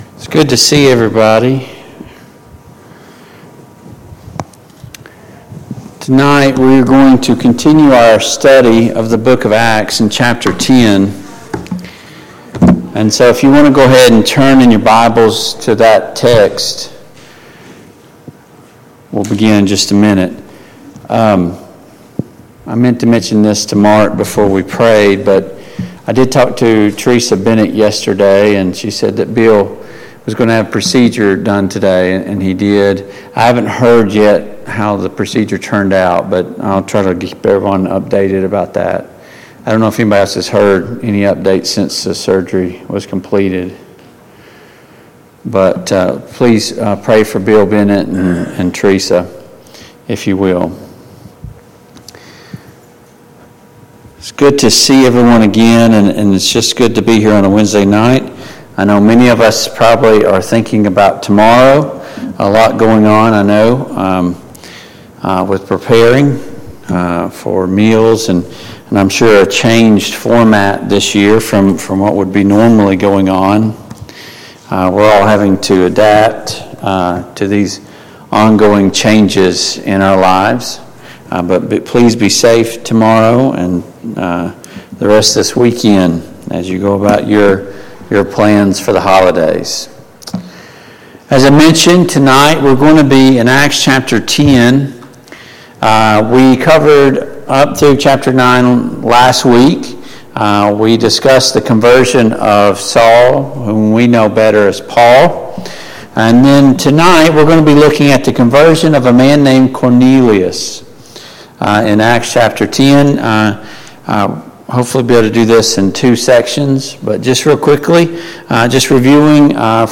Mid-Week Bible Study